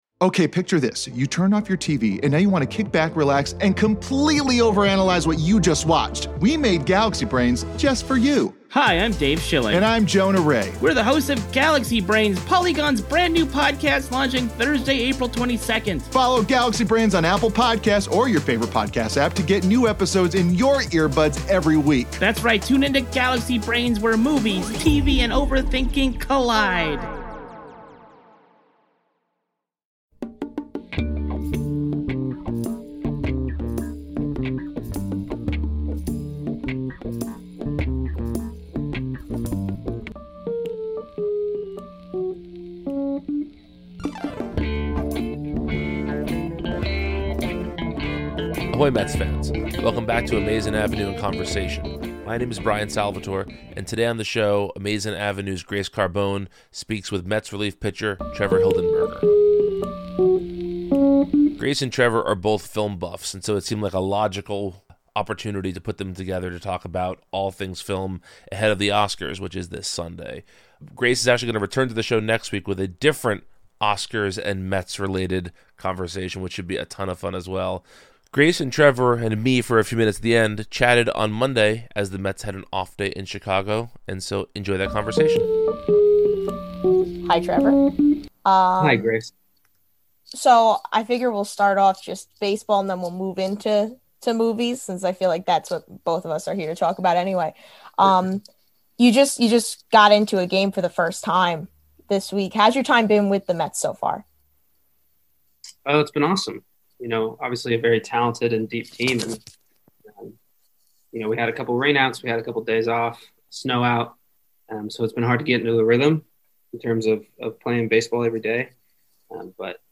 Welcome to Amazin’ Avenue in Conversation, a podcast from Amazin’ Avenue where we invite interesting people on the show to talk about themselves, the Mets, and more.